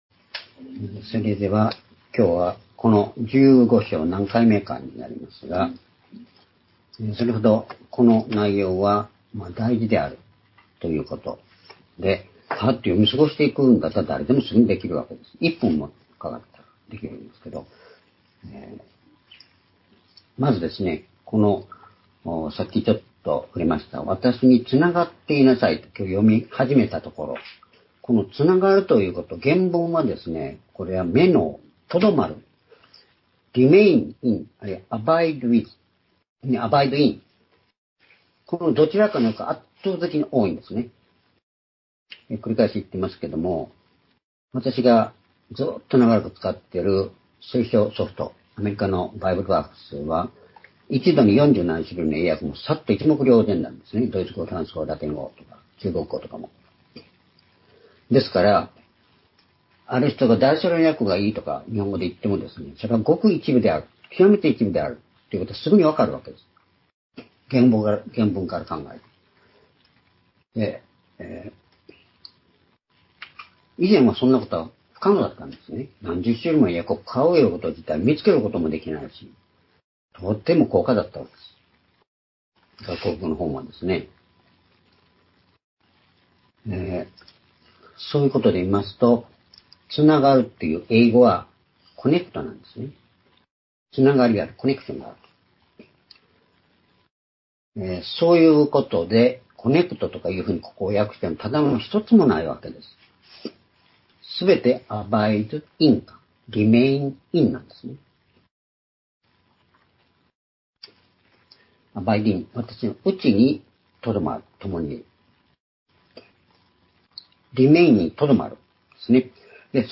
（主日・夕拝）礼拝日時 2024年6月2日(主日) 聖書講話箇所 「わが内に居れ」 ヨハネ15章4～10節 ※視聴できない場合は をクリックしてください。